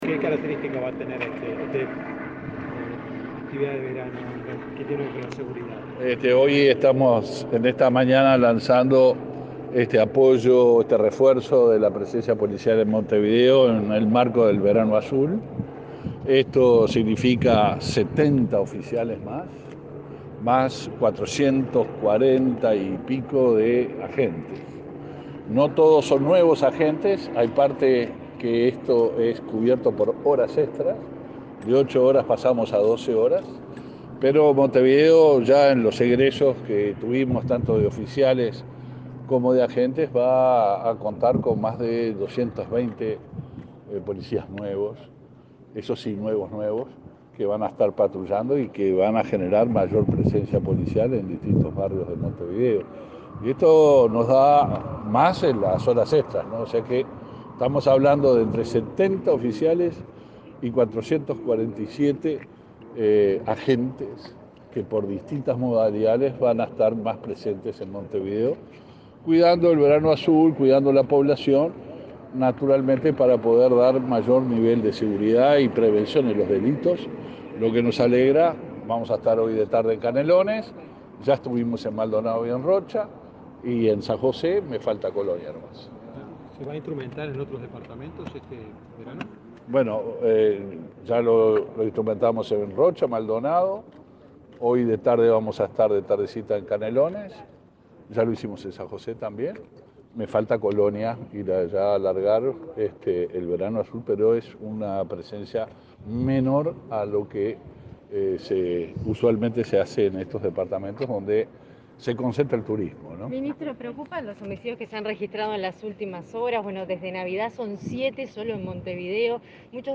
Declaraciones a la prensa del ministro del Interior, Luis Alberto Heber